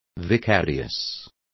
Complete with pronunciation of the translation of vicarious.